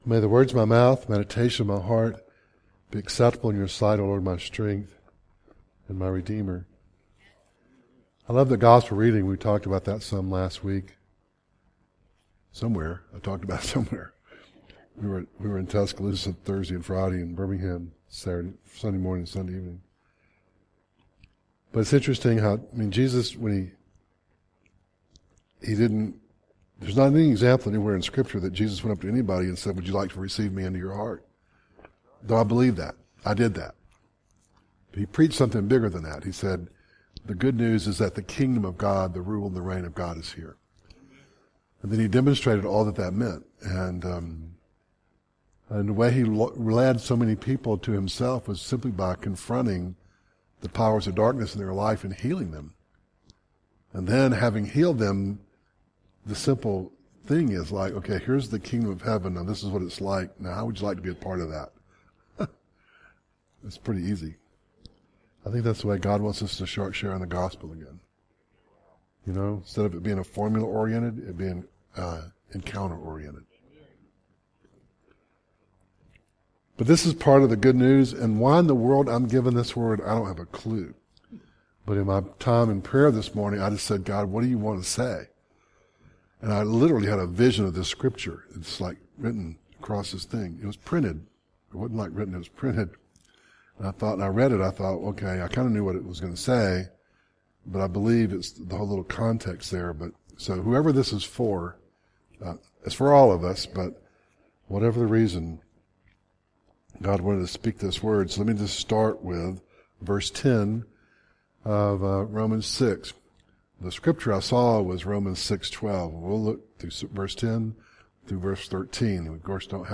Romans 6:1-10 Service Type: Conference